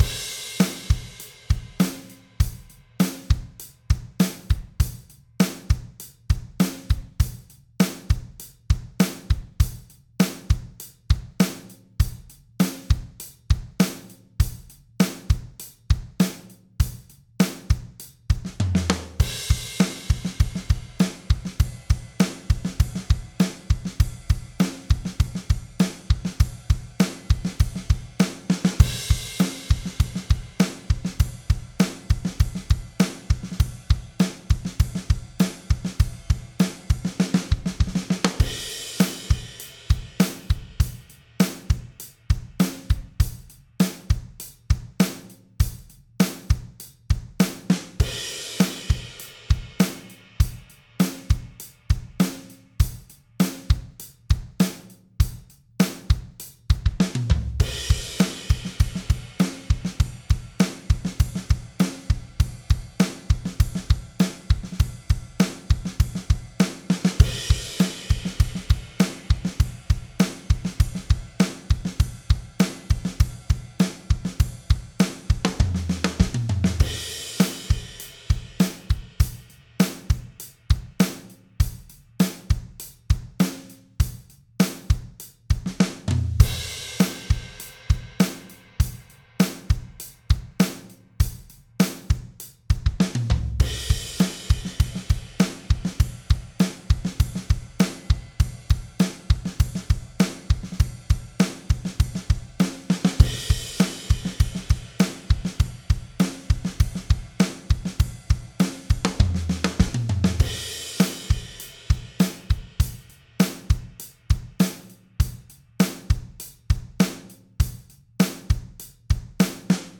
Cool Hendrix Style Blues E#9